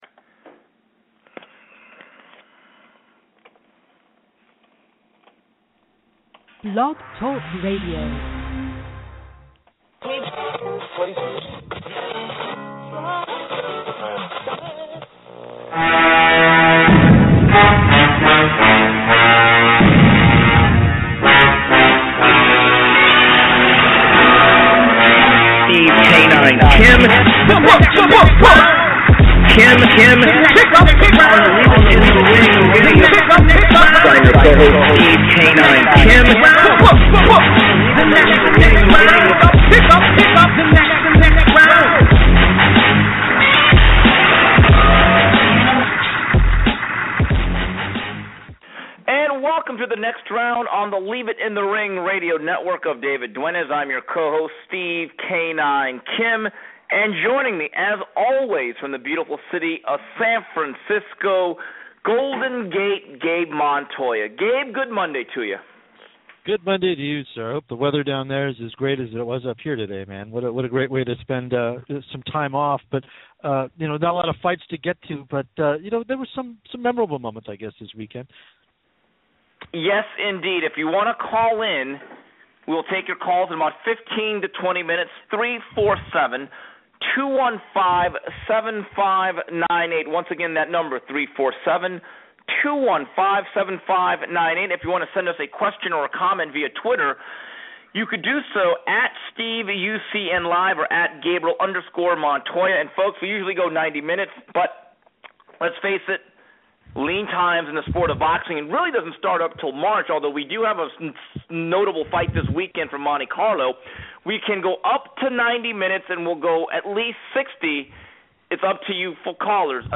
Plus, news, notes and your calls.